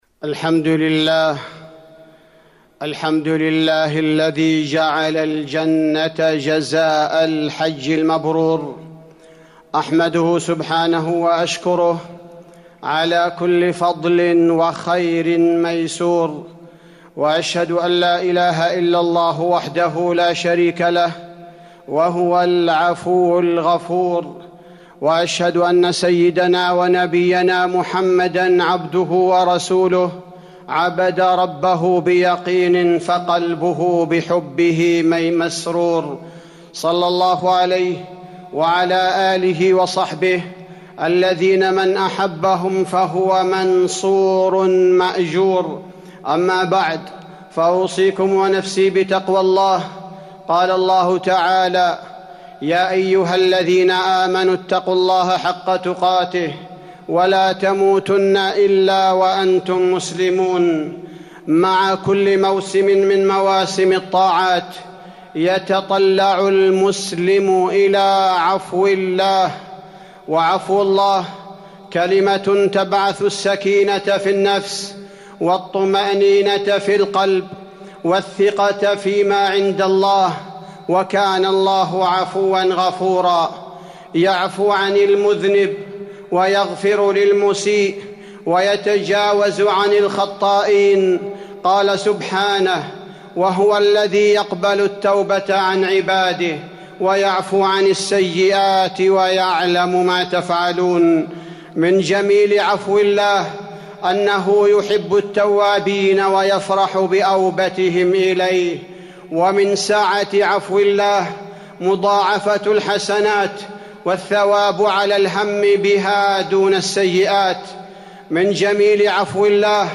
تاريخ النشر ١٠ ذو الحجة ١٤٤١ هـ المكان: المسجد النبوي الشيخ: فضيلة الشيخ عبدالباري الثبيتي فضيلة الشيخ عبدالباري الثبيتي سعة عفو الله